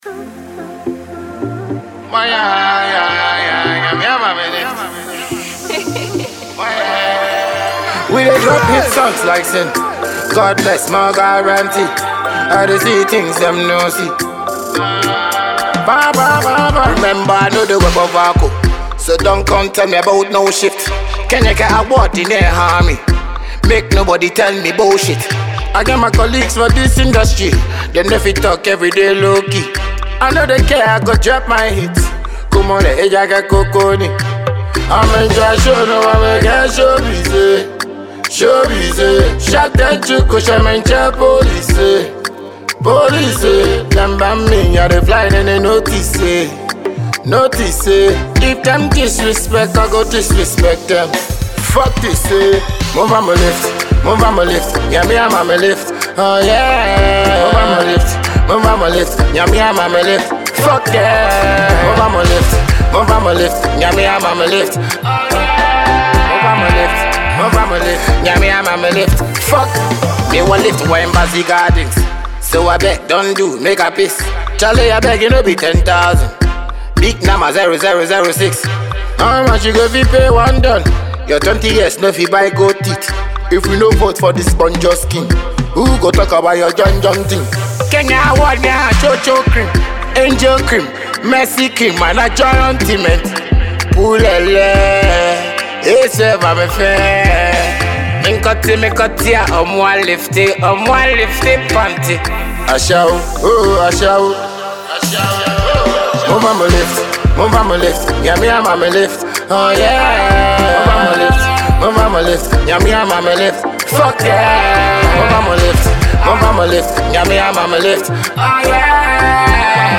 Ghana MusicMusic